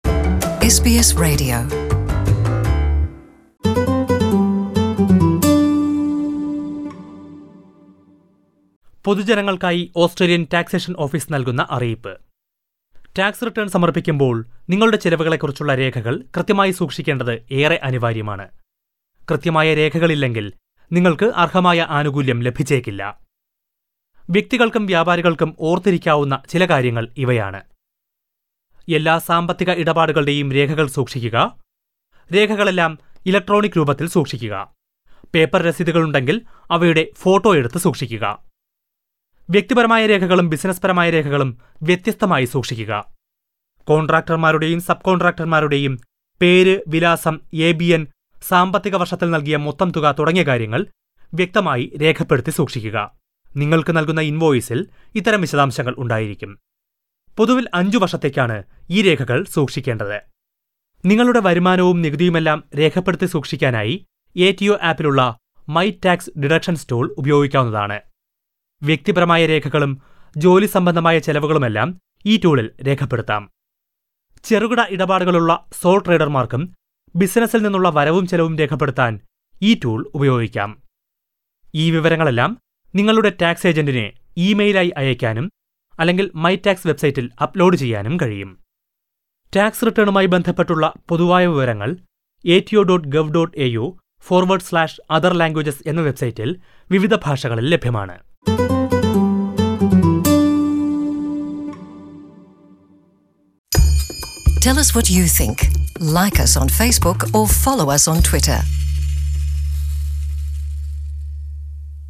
പൊതുജനങ്ങൾക്കായി ഓസ്‌ട്രേലിയൻ ടാക്സേഷൻ ഓഫീസ് നൽകുന്ന അറിയിപ്പ്